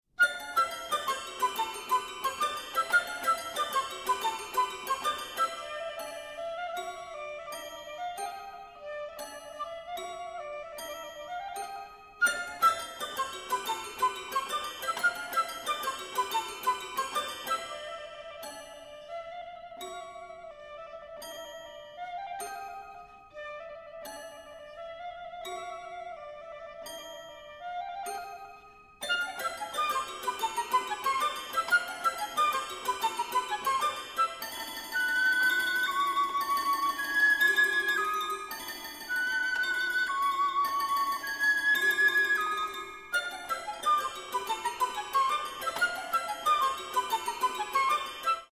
piccolo
toy piano